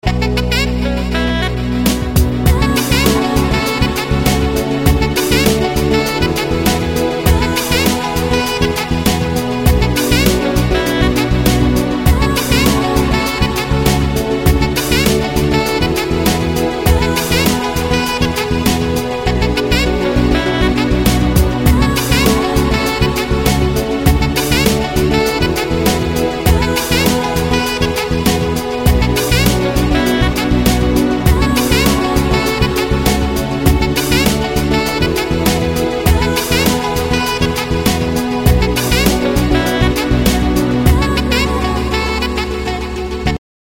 • Качество: 128, Stereo
Саксофон